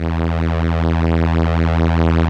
Index of /90_sSampleCDs/Keyboards of The 60's and 70's - CD1/STR_Elka Strings/STR_Elka Cellos
STR_ElkaVcE_3.wav